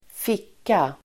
Uttal: [²f'ik:a]